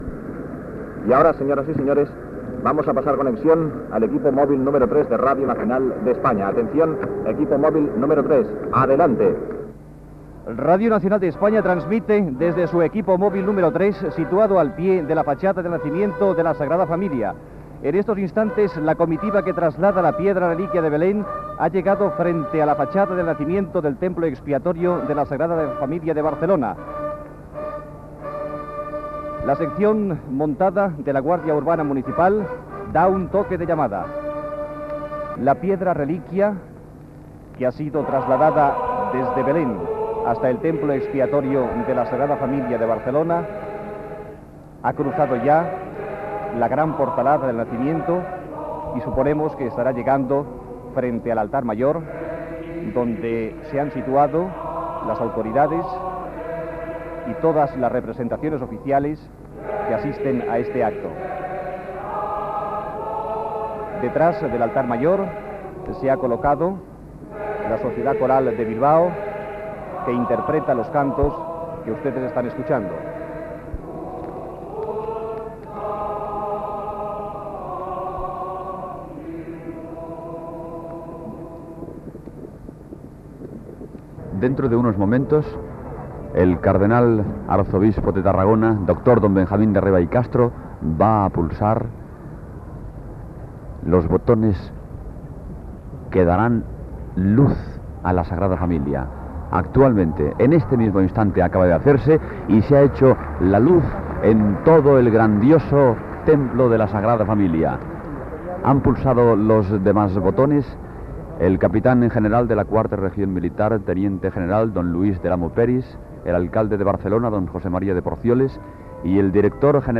Connexió amb l'exterior de la Sagrada Família de Barcelona, el dia de l'arribada de la pedra sagrada, portada de Betlem i de la inauguració de la il·luminació de la façana del naixement i de la il·luminació nadalenca de la ciutat de Barcelona
Informatiu